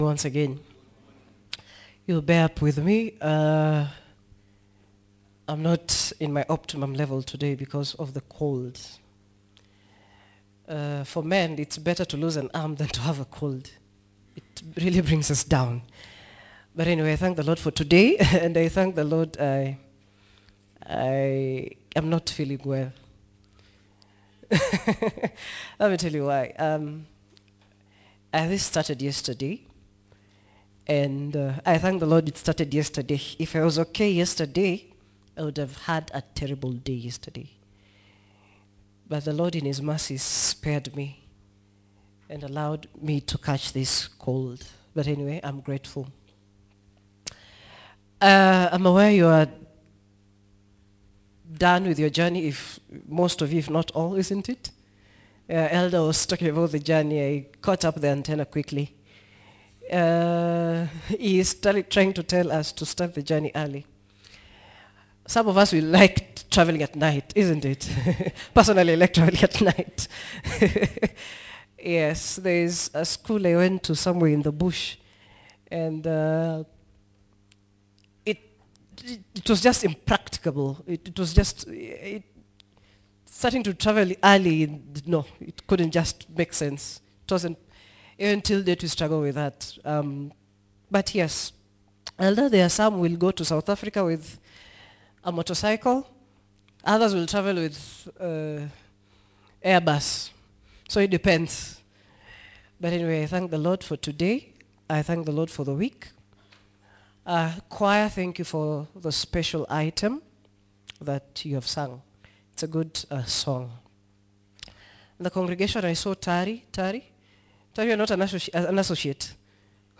Tuksda Church -Sermons